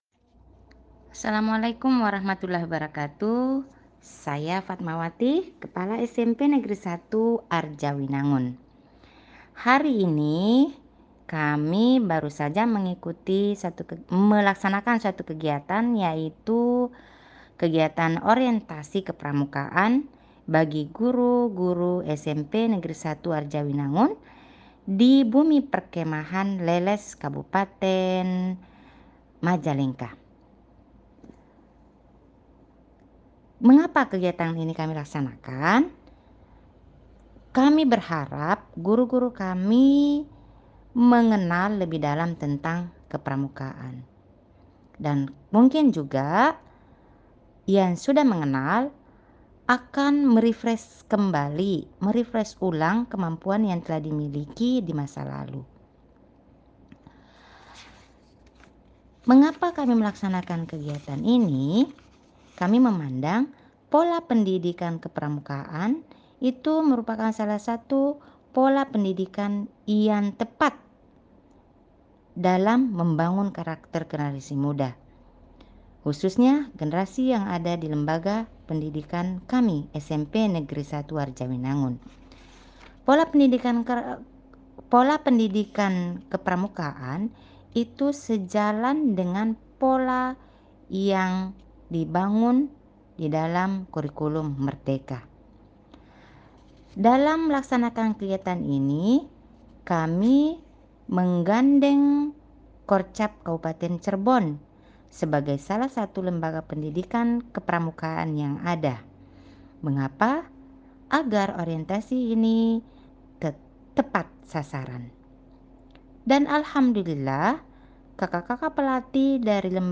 Sambutan lengkap